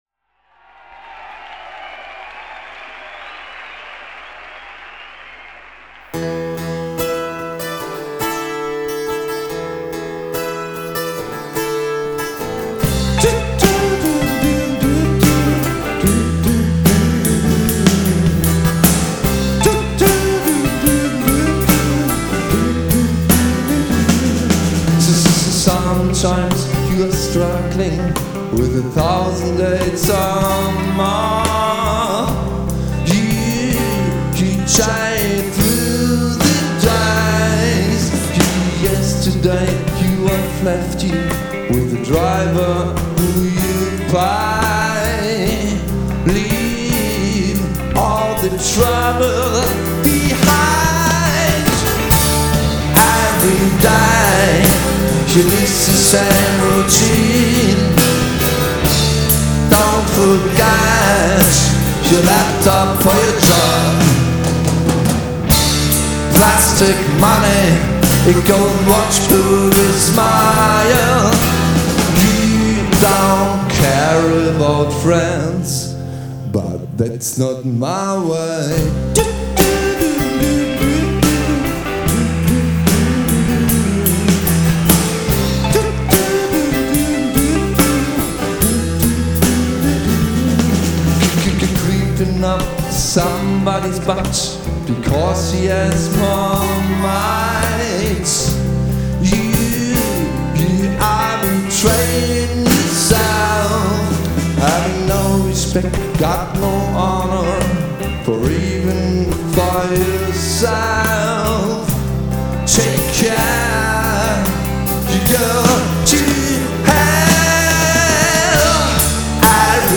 Genre: Alternative Indie Rock Pop.